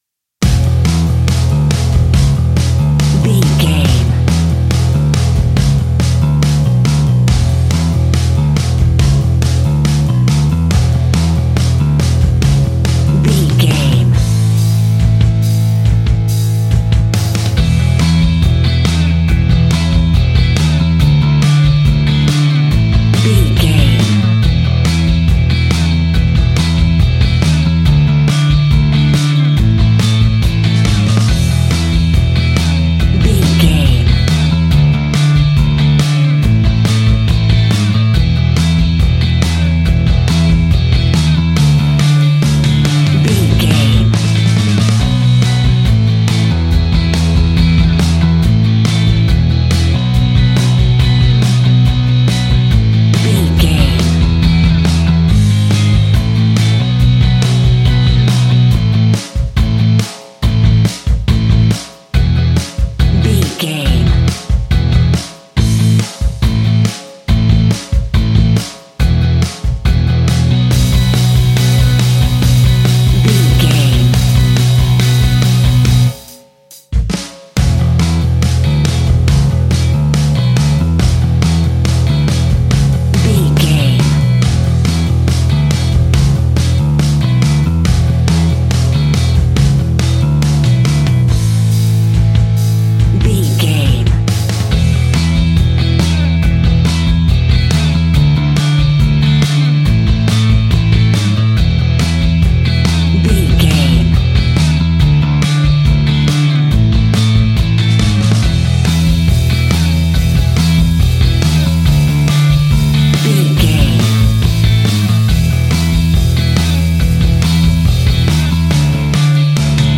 Ionian/Major
indie pop
fun
energetic
uplifting
instrumentals
upbeat
groovy
guitars
bass
drums
piano
organ